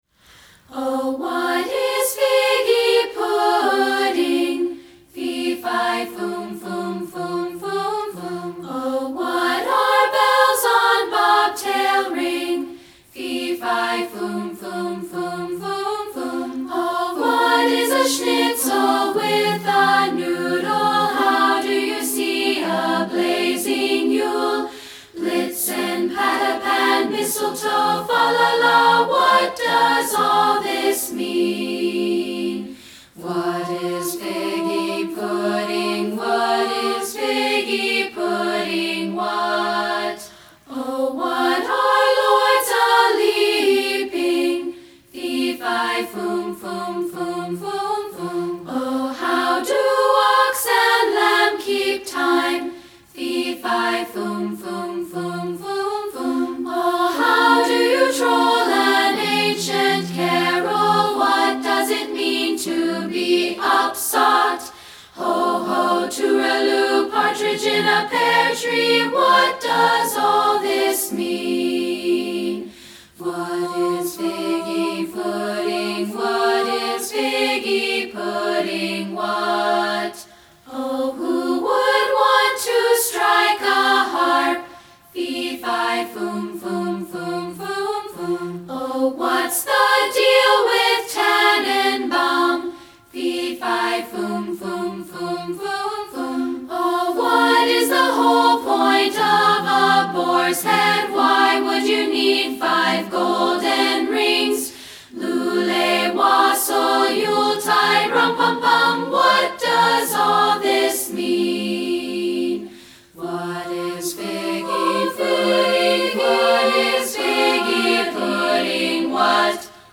- A Cappella Version
Here is the a cappella version.